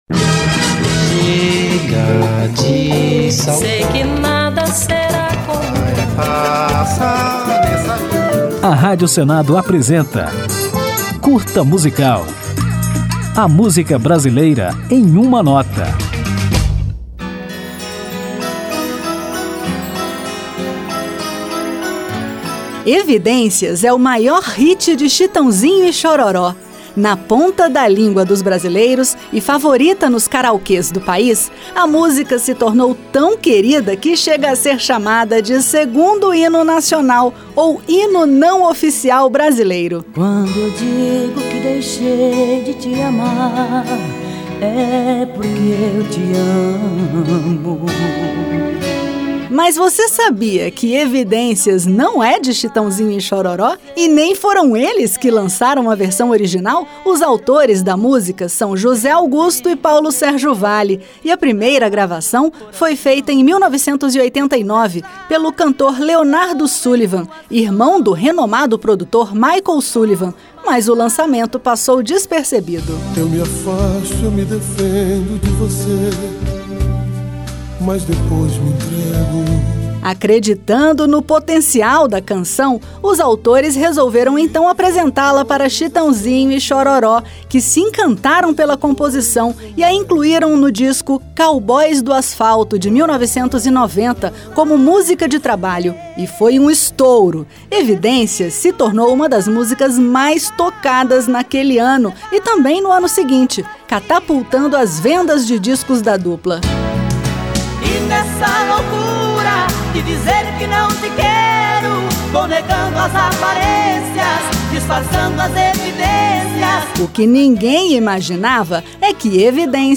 Depois de conferida a história, as curiosidades e a polêmica em torno de Evidências, vamos ouvi-la na gravação mais famosa da música, lançada em 1990, por Chitãozinho e Xororó.